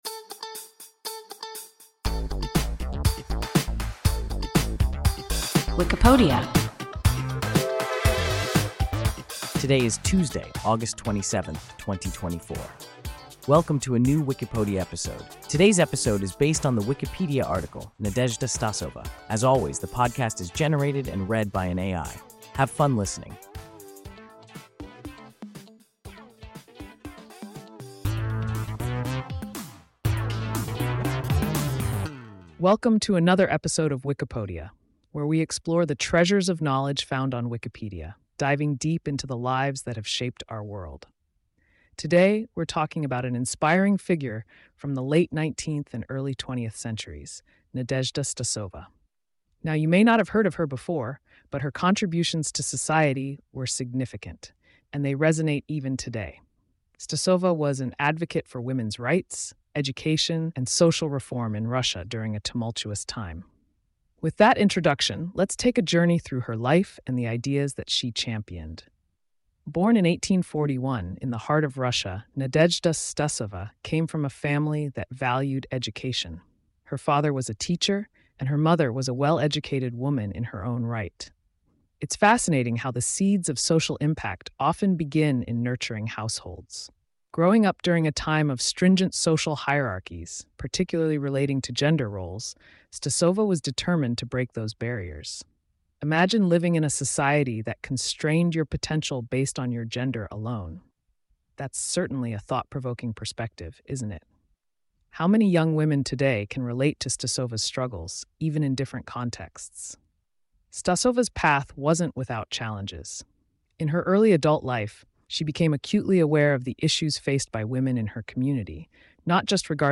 Nadezhda Stasova – WIKIPODIA – ein KI Podcast